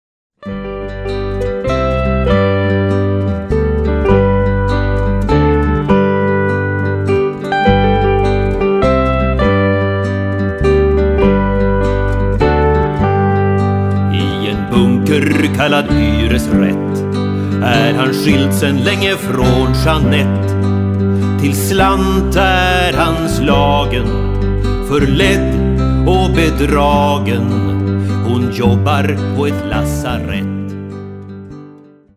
dragspel
oktav- och elgitarr, bas och kör
synth och ljudeffekter
Text, sång, musik och album: